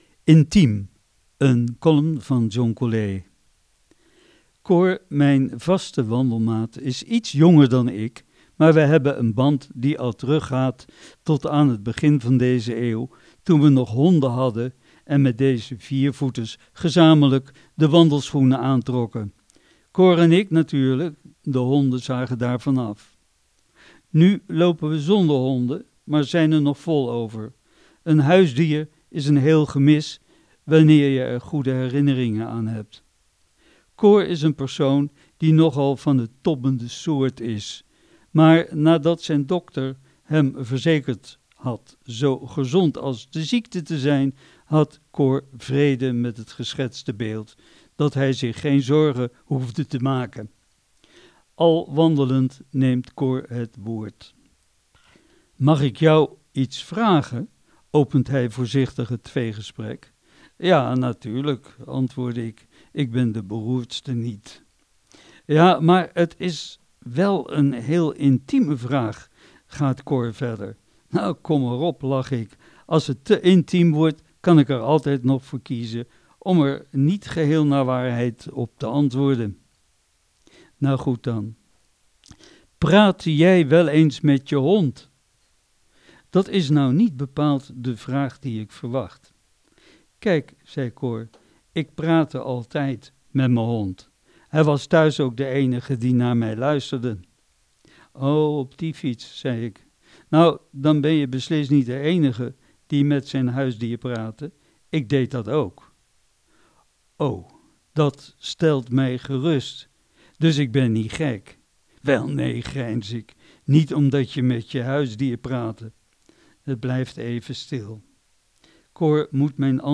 Column